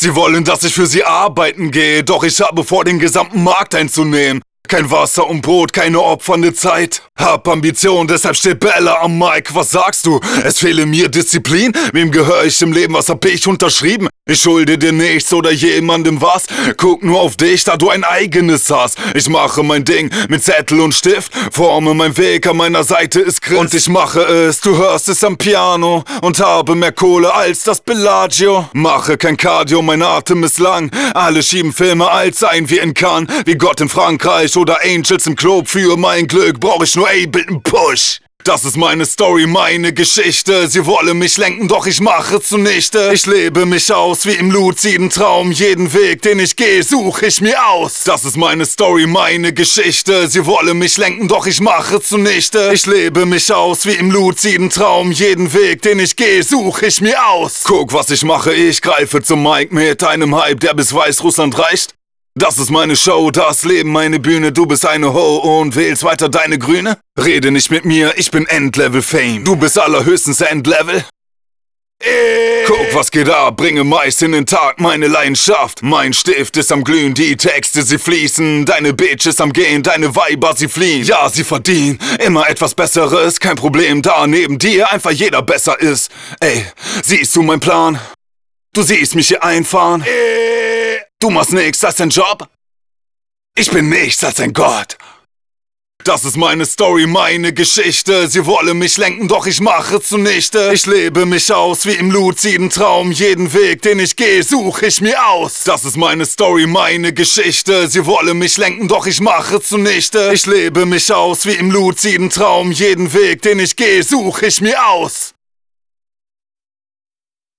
Das liegt an der Aufnahme. Selbst mit allen mir zur Verfügung stehenden Mitteln ist es mir nur gelungen den miserablen Klang deutlicher zu bekommen.